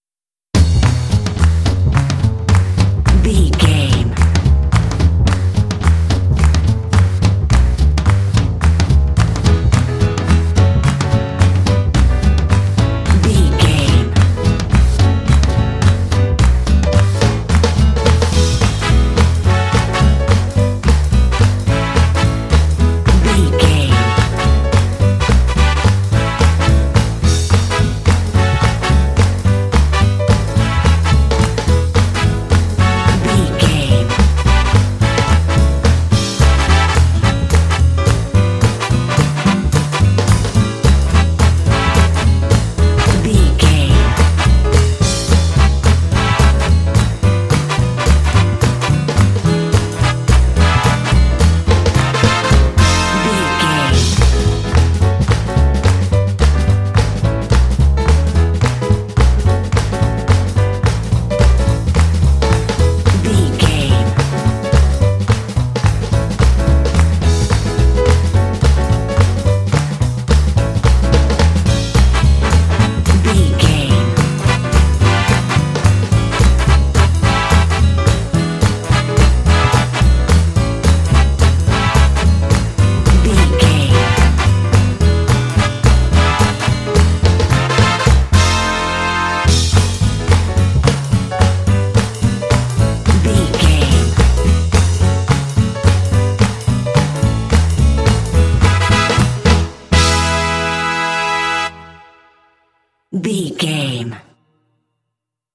Uplifting
Mixolydian
Fast
energetic
bouncy
joyful
double bass
drums
piano
brass
big band
jazz